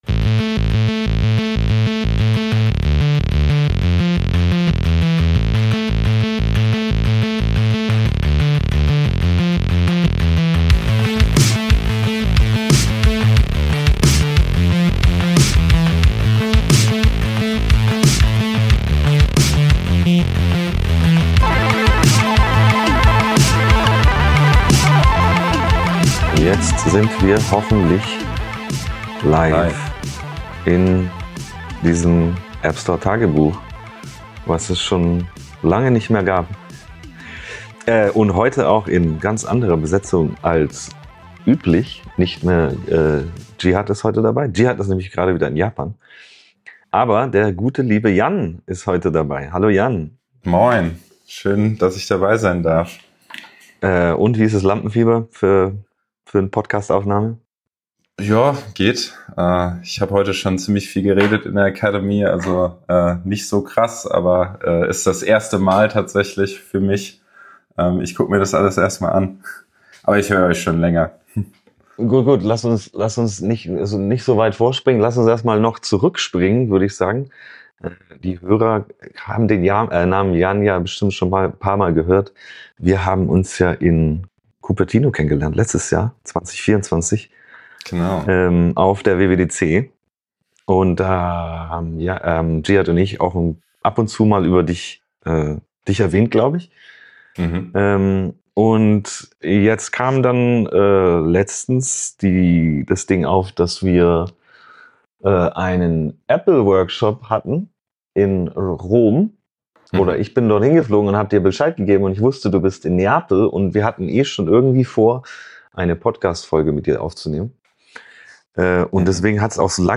Ein Podcast von zwei App Entwicklern